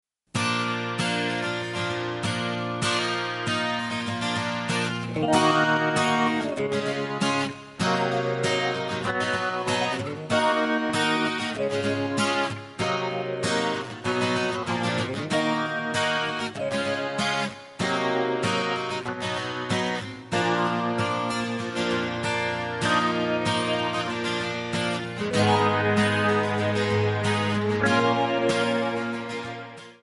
Backing track files: Pop (6706)
Buy With Backing Vocals.